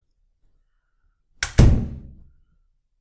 دانلود آهنگ بسته شدن در از افکت صوتی اشیاء
دانلود صدای بسته شدن در از ساعد نیوز با لینک مستقیم و کیفیت بالا
جلوه های صوتی